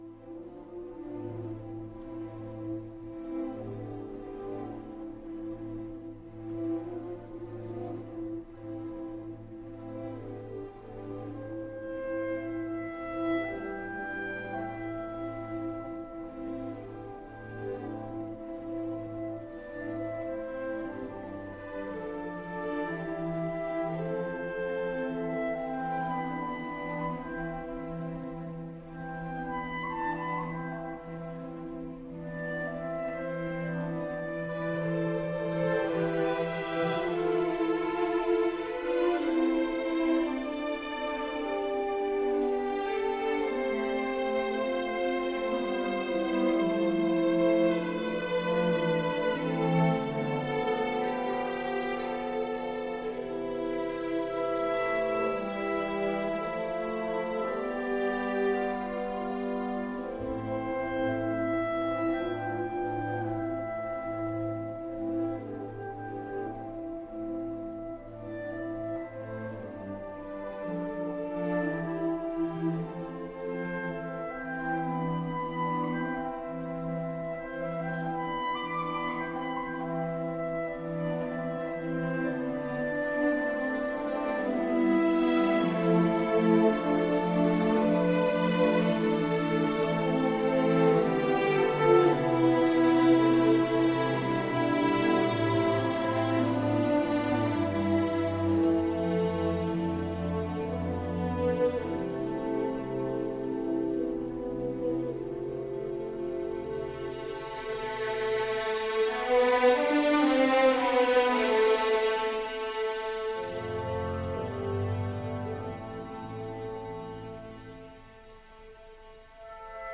Boat or swim the waters of this jewel..not quite marooned on a desert isle, Grey Havens islands are green and there’s a handy TUFF motor boat at the dock, but the sense of solitude is there…amid the whale songs and the soothing surf.